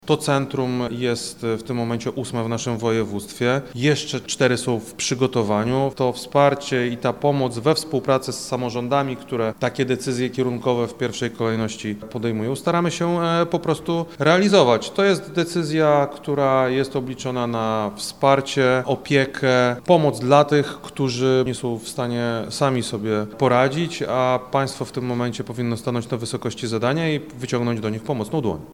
O powstaniu kolejnych – mówi wojewoda lubelski Krzysztof Komorski: